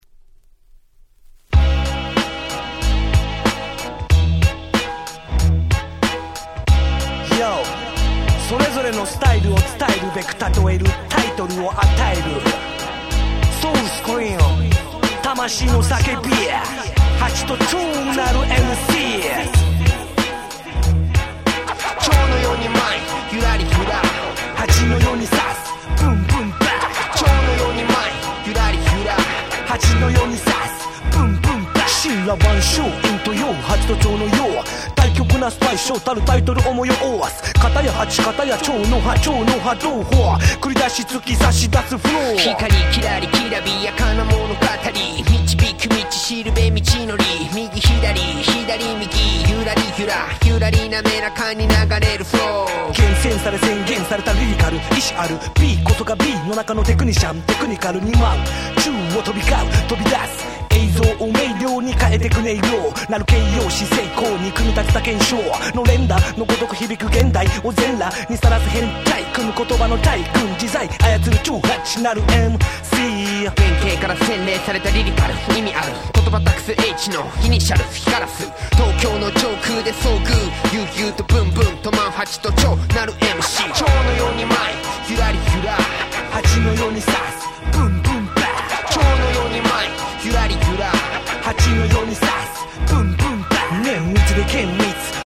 00' Japanese Hip Hop Super Classic !!
J-Rap 日本語ラップ